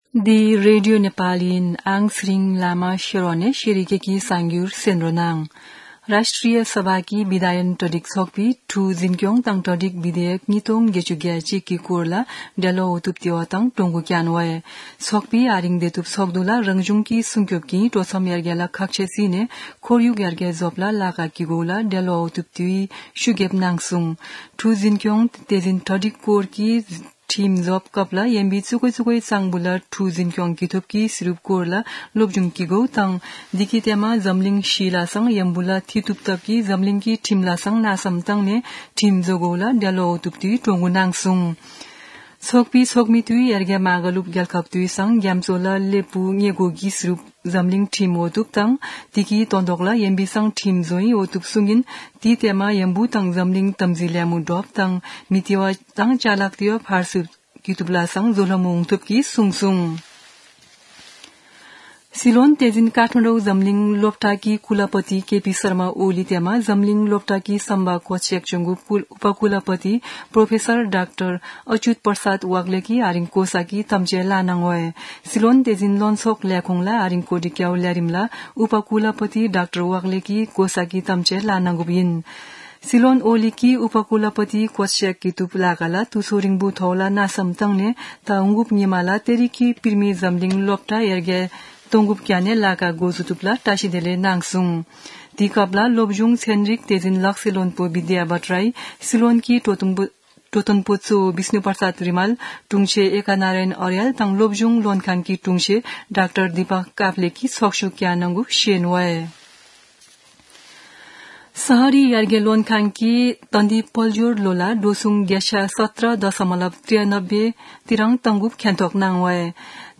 शेर्पा भाषाको समाचार : ४ चैत , २०८१
shearpa-news.mp3